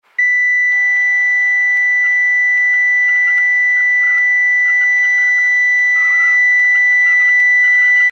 На этой странице собраны звуки кардиомониторов — от ровного ритма здорового сердца до тревожных сигналов критических состояний.
Звук писка кардиомонитора при остановке сердца (ровная линия)